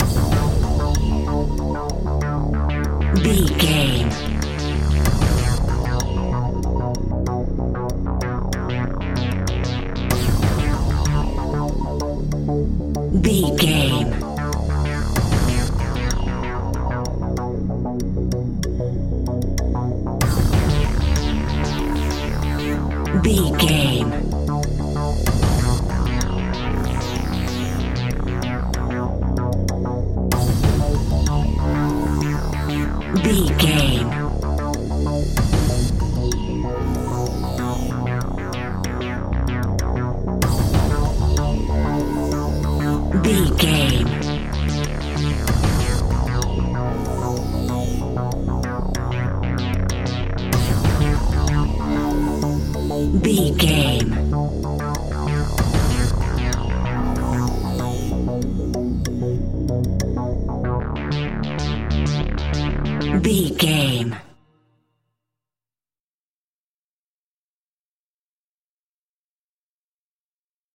Aeolian/Minor
ominous
dark
eerie
cello
synthesiser
percussion
drums
horror music